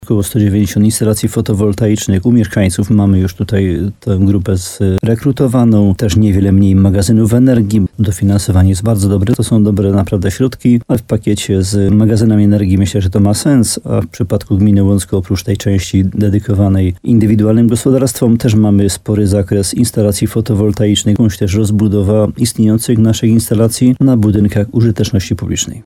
– W klastrze jest sześć sądeckich gmin, a dla samego Łącka przypadnie kwota prawie 12 mln złotych – poinformował w programie Słowo za Słowo w radiu RDN Nowy Sącz, wójt gminy Łącko Jan Dziedzina.